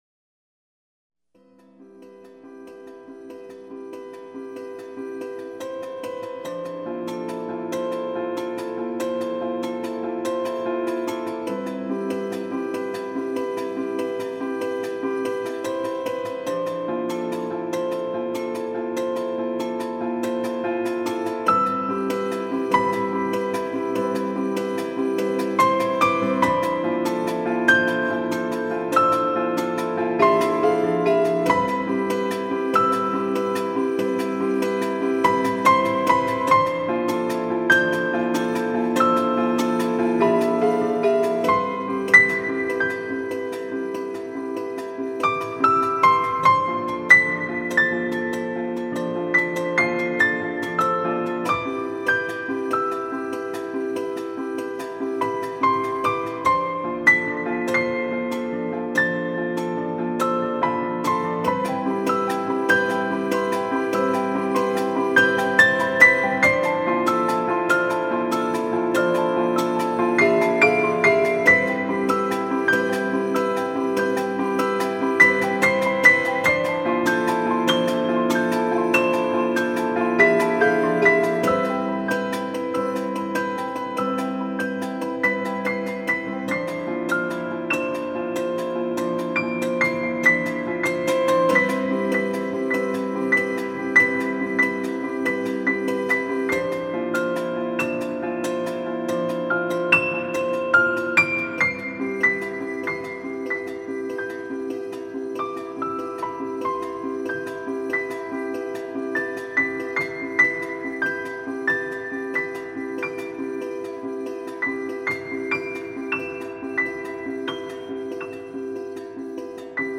版本：钢琴曲
运用了技术钢琴的敲打音乐，细听之下会让人以为是异国韵律的原始音乐的错觉，旋律新鲜接近多种多样的效果。